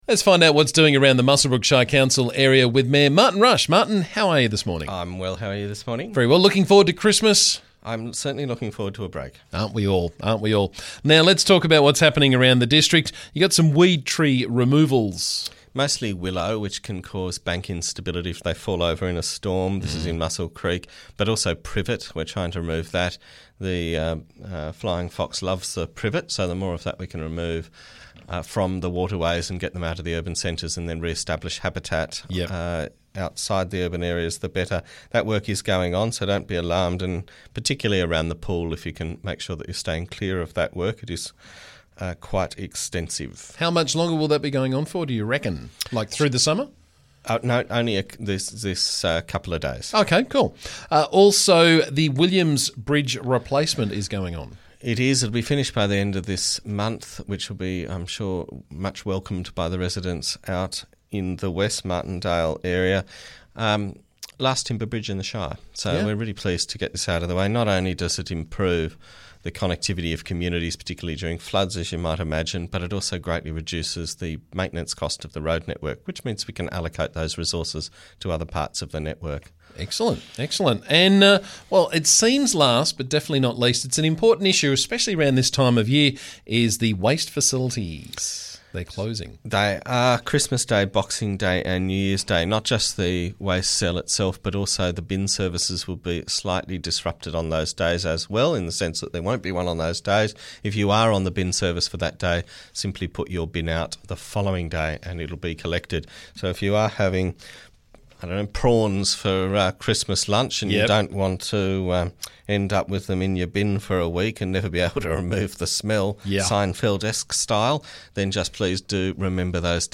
Muswellbrook Shire Council Mayor Martin Rush joined me to talk about the latest from around the district.